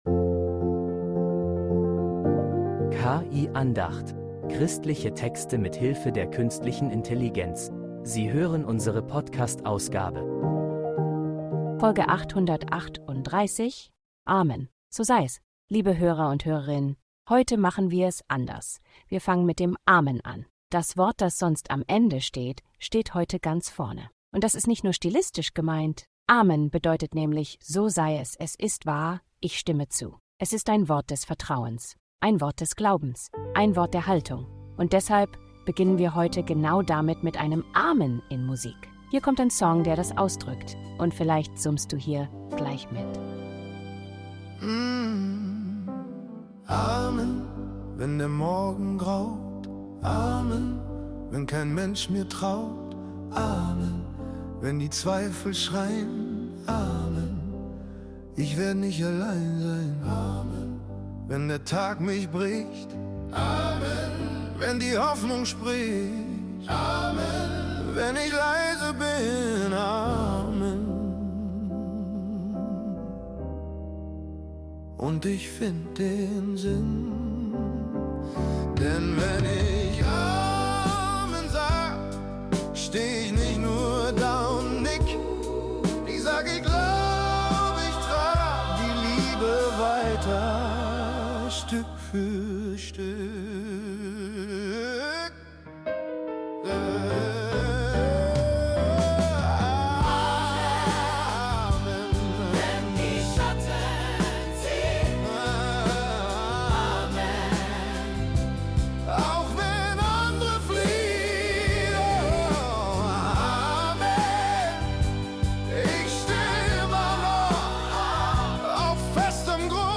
Christliche Texte mit Hilfe der Künstlichen Intelligenz